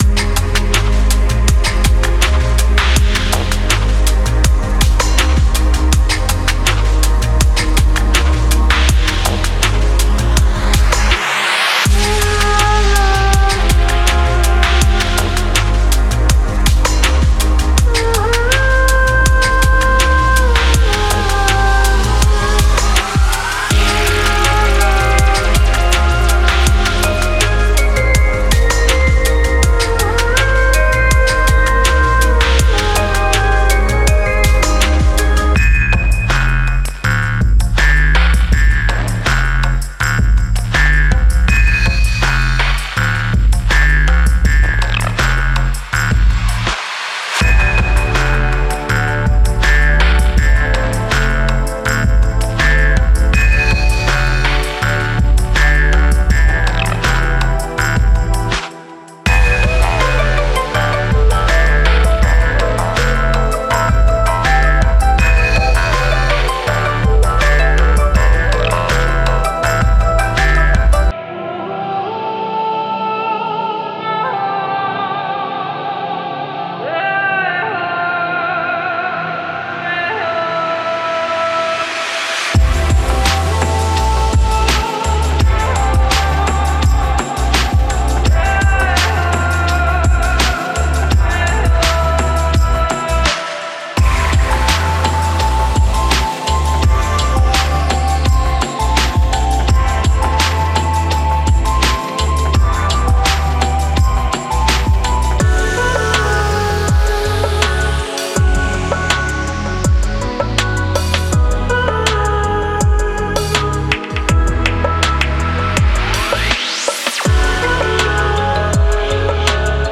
Genre:Chillout
くつろぎ、深呼吸し、穏やかなサウンドスケープに身をゆだねましょう。
アンビエントなパッドとドローン
夢のようなシンセループとテクスチャー
やさしいパーカッションとダウンテンポグルーヴ
柔らかなピアノのモチーフとメロディック要素
自然にインスパイアされたフィールドレコーディングとFX